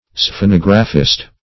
Sphenographist \Sphe*nog"ra*phist\, n.